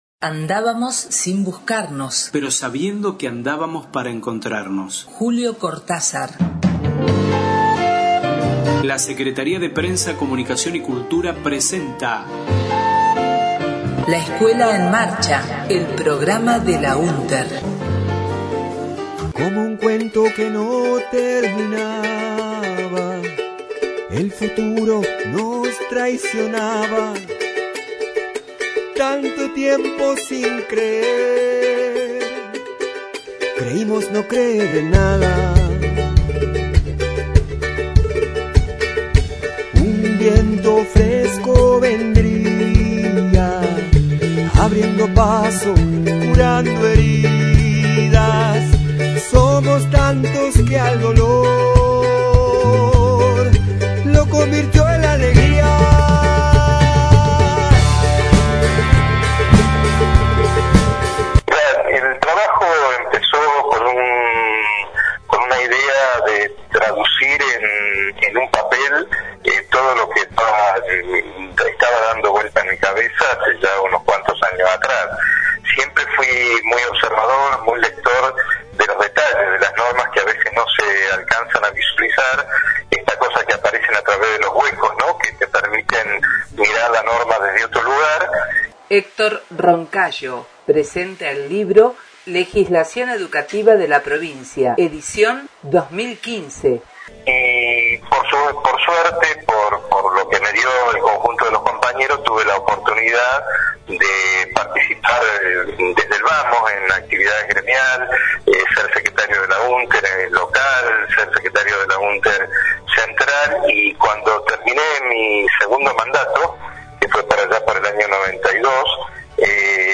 La Escuela en Marcha Radio La Escuela en Marcha Plenario de Delegadas/os